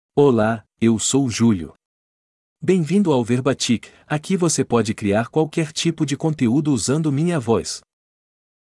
Julio — Male Portuguese (Brazil) AI Voice | TTS, Voice Cloning & Video | Verbatik AI
JulioMale Portuguese AI voice
Julio is a male AI voice for Portuguese (Brazil).
Voice sample
Listen to Julio's male Portuguese voice.
Male